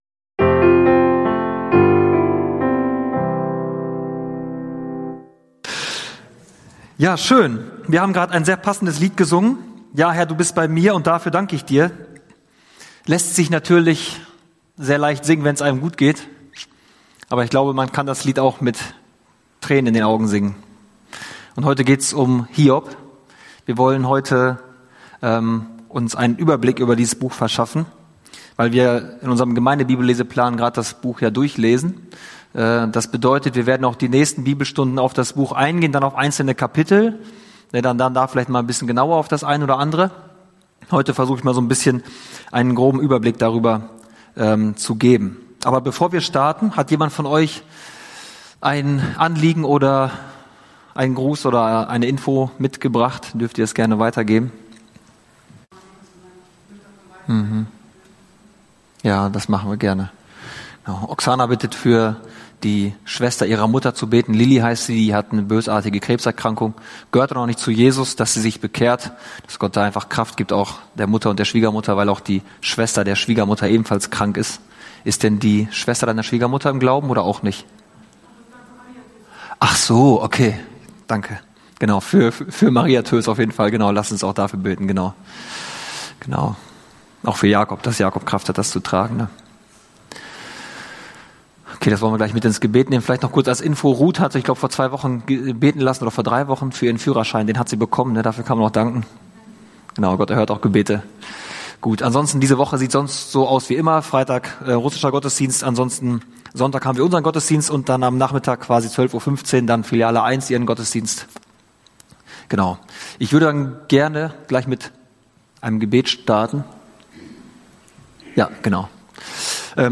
Höre inspirierende Predigten und lerne Jesus besser kennen.